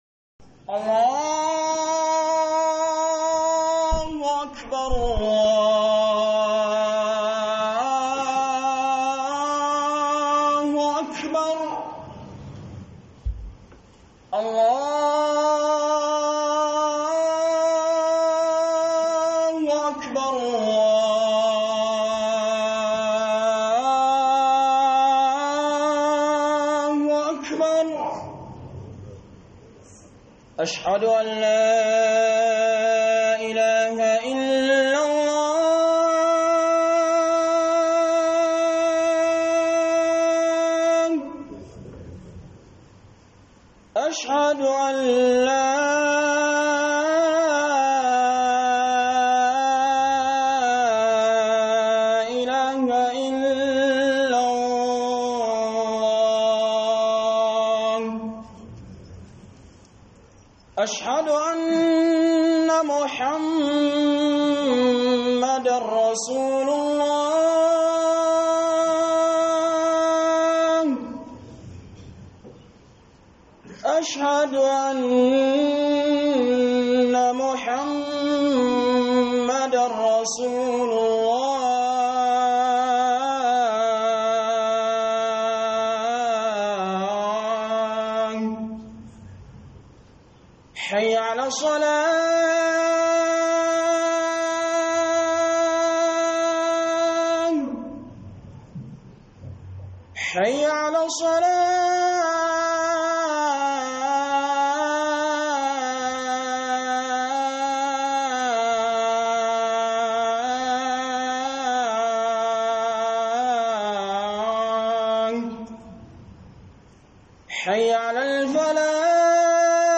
AMANA 4 - Huduba